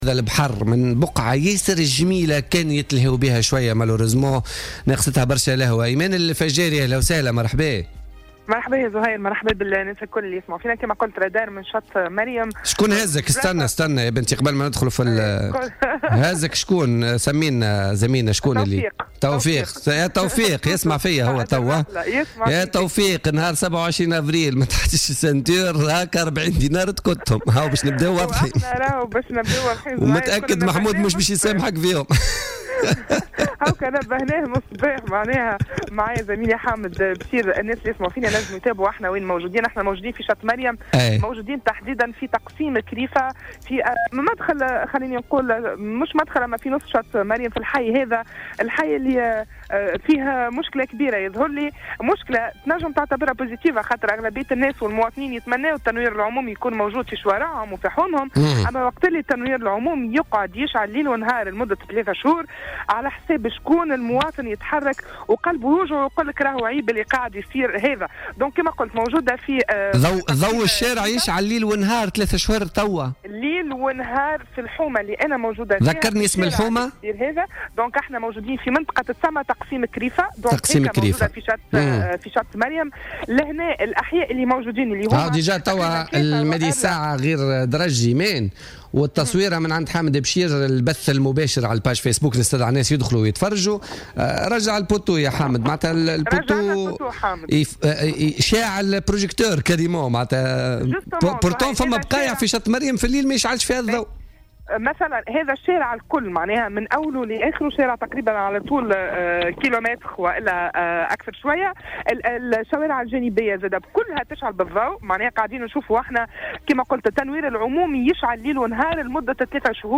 تنقل الرادار اليوم الثلاثاء إلى منطقة شط مريم بسوسة وتحديدا في حي تقسيم كريفة والذي يعاني من ظاهرة غريبة وهي الانارة العمومية التي لم تنقطع ليلا ونهارا منذ حوالي 3 أشهر .
وأكد أحد المواطنين للجوهرة "اف ام" أن سكان هذا الحي قاموا عدة مرات بتبليغ الإدارات المعنية حتى يتم ايقاف الإنارة نهارا دون جدوى إذ رفضت كل الإدارات الإستجابة لمطالبهم وتنصلت كل واحدة من مسؤولياتهم مدعين أن الأمر ليس من مشمولاتهم .